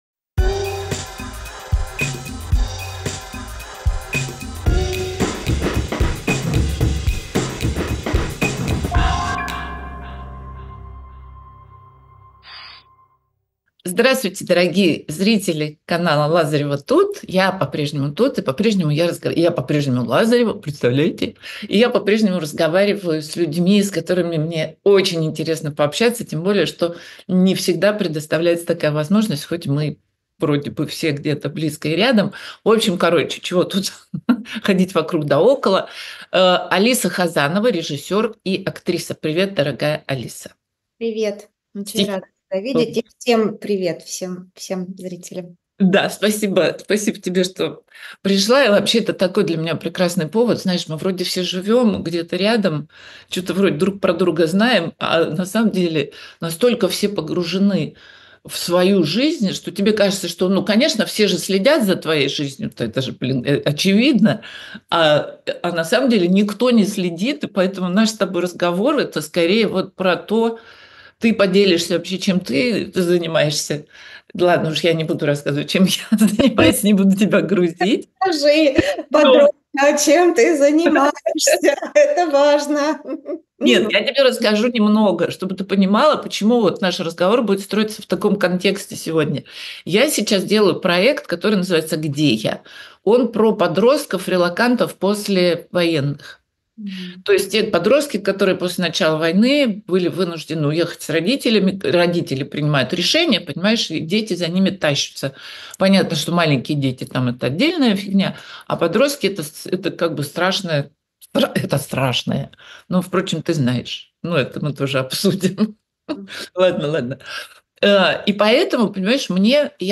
Эфир ведёт Татьяна Лазарева